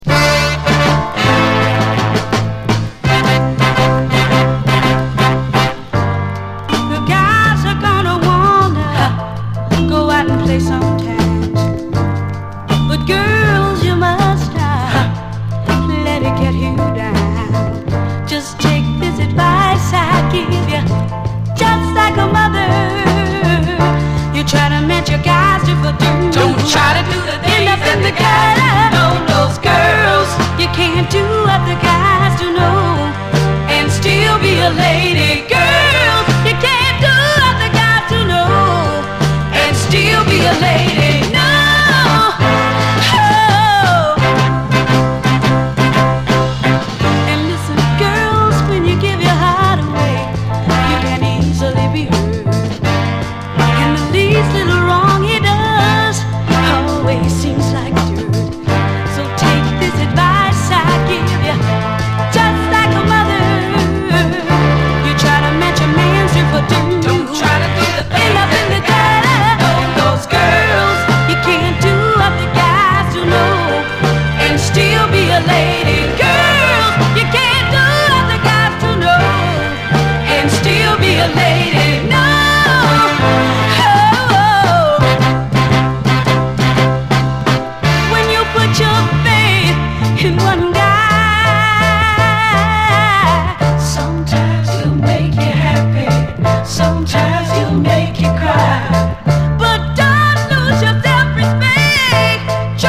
SOUL, 60's SOUL, 7INCH
パワフルな哀愁系ミディアム・ソウル！